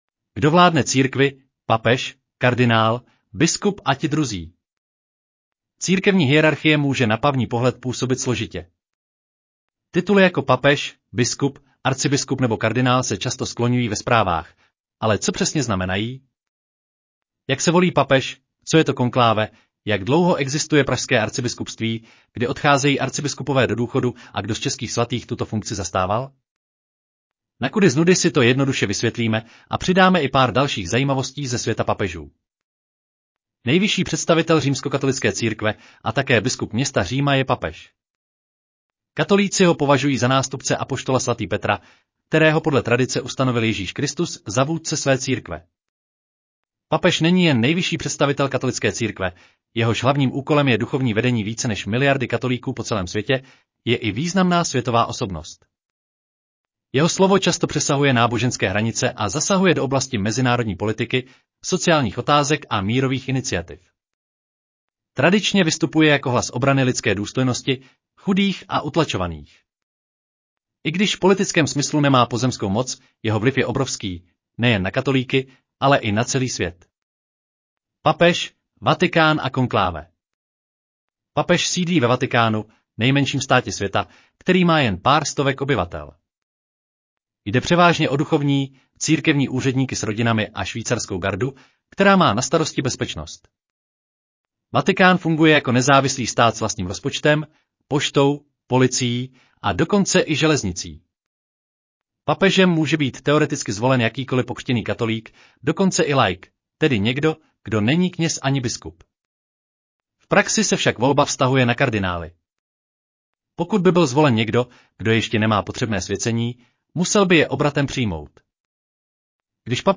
Audio verze článku Tradice: biskupové a arcibiskupové, církevní hodnostáři a čeští svatí